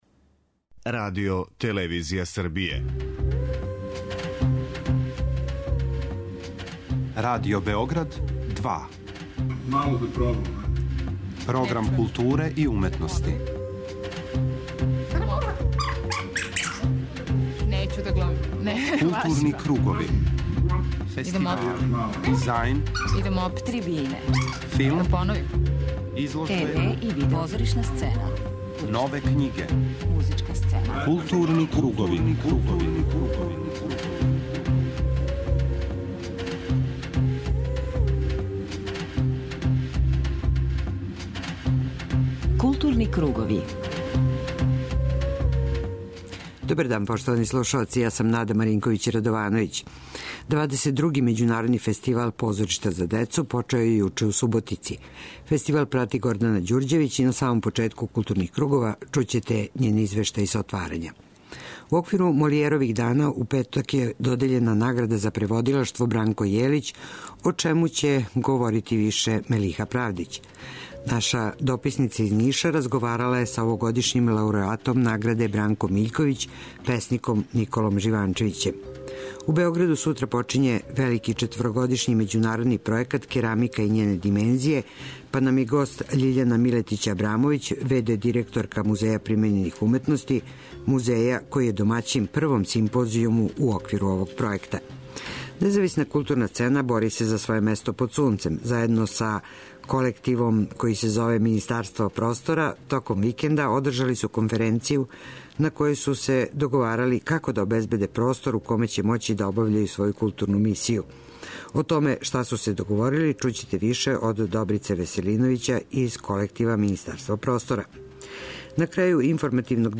У оквиру тематског блока Арс сонора , емитоваћемо и репортажу снимљену на овогодишњем фестивалу Ринг Ринг .
преузми : 53.02 MB Културни кругови Autor: Група аутора Централна културно-уметничка емисија Радио Београда 2.